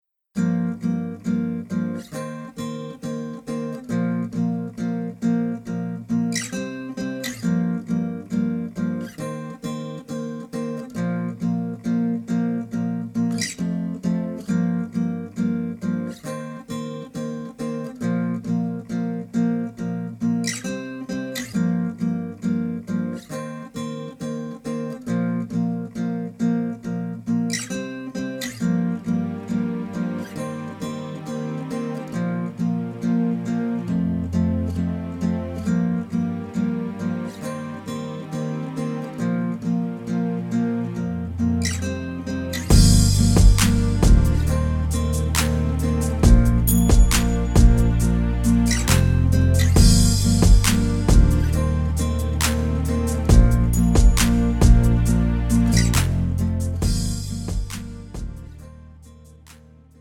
장르 pop 구분 Pro MR
Pro MR은 공연, 축가, 전문 커버 등에 적합한 고음질 반주입니다.